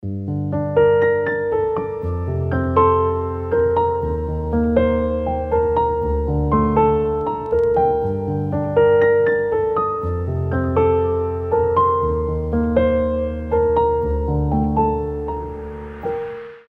• Качество: 320, Stereo
грустные
спокойные
без слов
красивая мелодия
пианино
Лирическая музыка